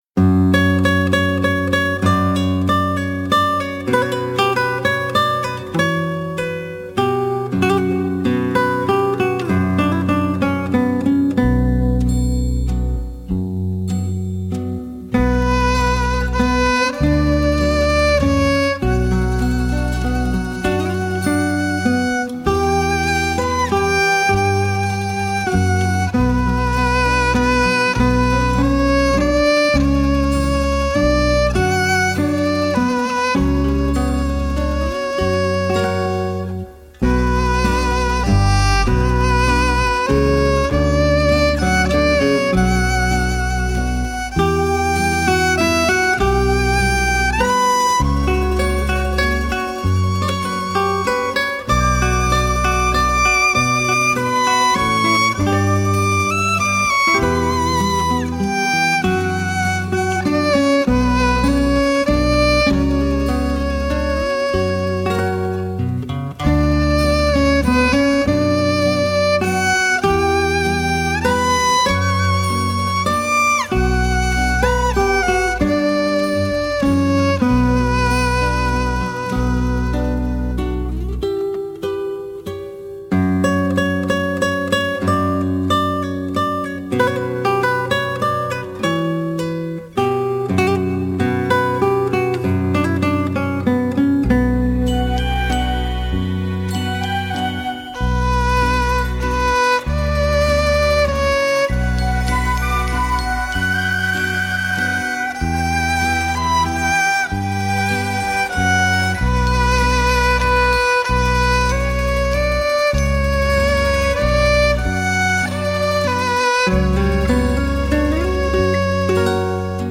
二胡演奏日本演歌
二胡演奏
心の底まで癒される、二胡の音色